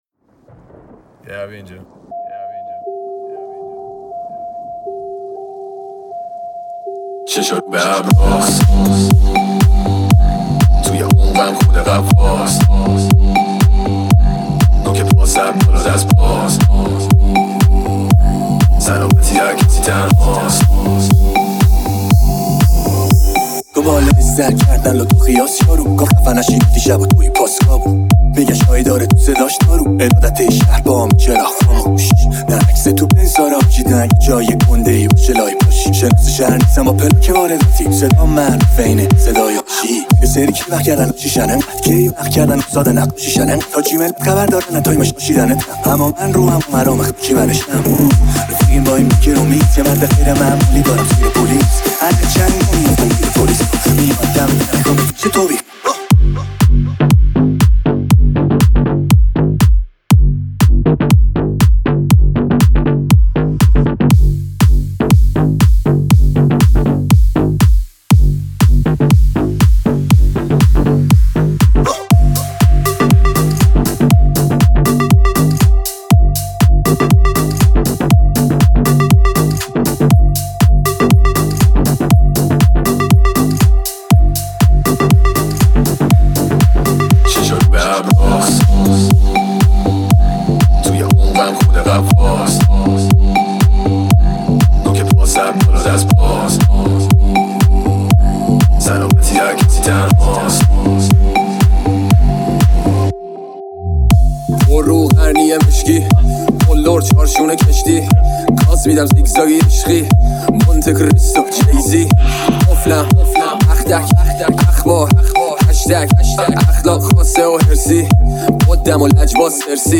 ریمیکس Remix
ریمیکس آهنگ رپ